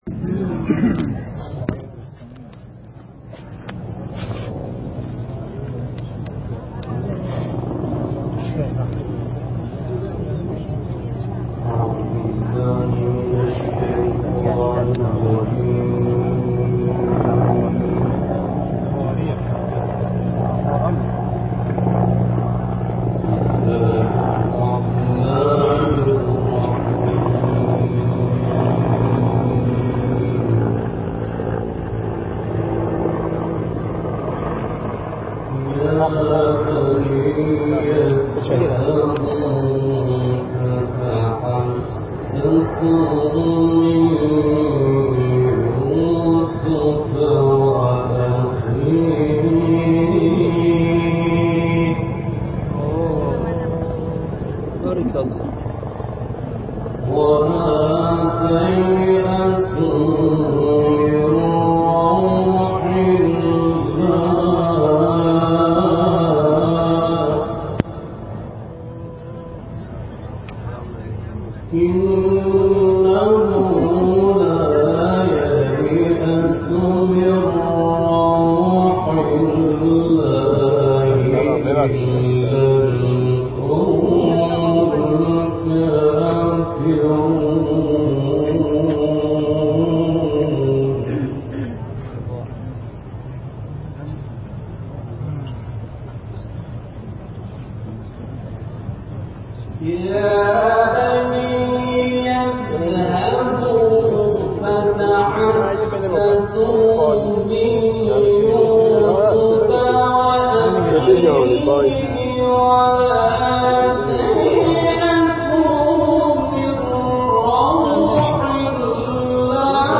تلاوت
در مراسم دعای عرفه سرزمین عرفات
در مراسم دعای عرفه حجاج ایرانی در سرزمین عرفات